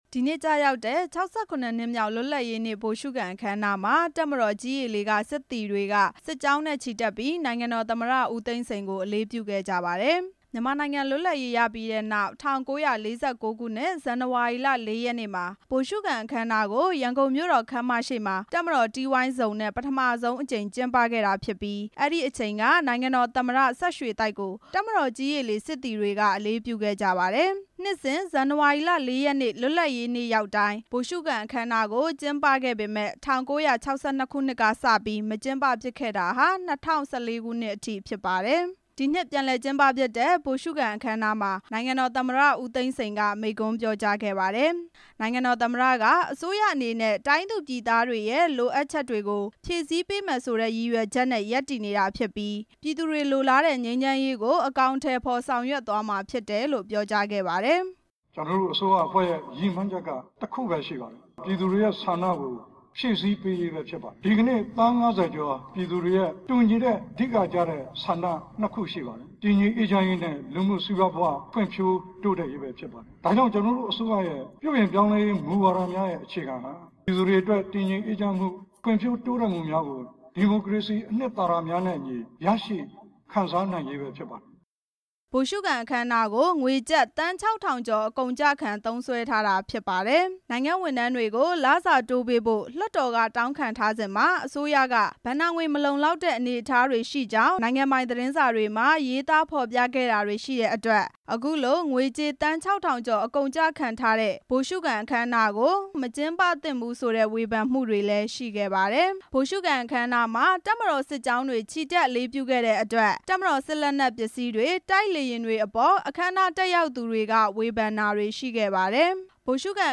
နေပြည်တော်မှာ ကျင်းပတဲ့ ၆၇ နှစ်မြောက် လွတ်လပ်ရေးနေ့ ဗိုလ်ရှုခံအခမ်းအနား မှာ နိုင်ငံတော်သမ္မတ ဦးသိန်းစိန်က အခုလိုထည့်သွင်းပြောကြားခဲ့တာဖြစ်ပါတယ်။